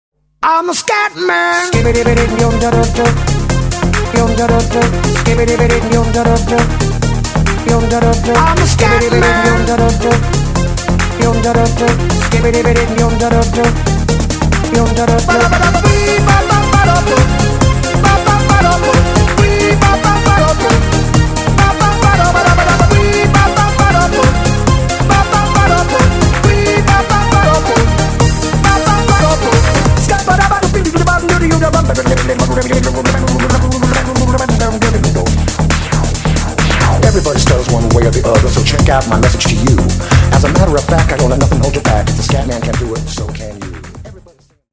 мужской вокал
90-е
бодрые
Euro House